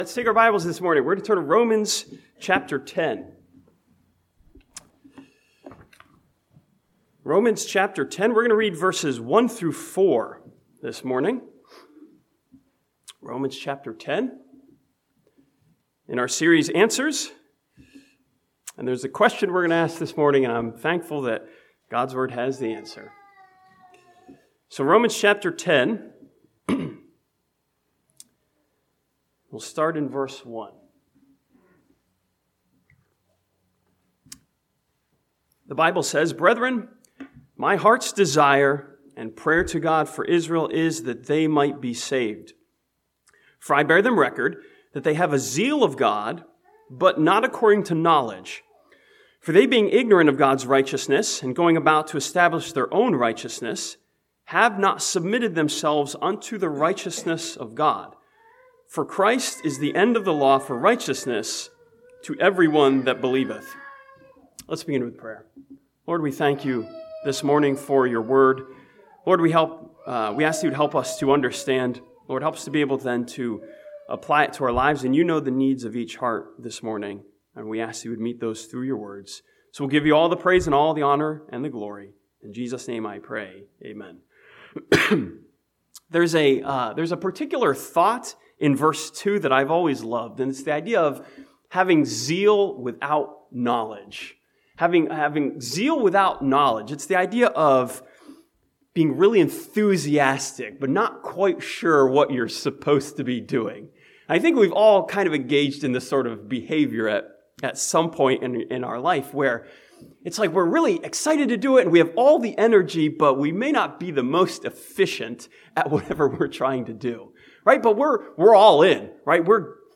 This sermon from Romans chapter 10 gives an answer to the question of understanding about what we need to know.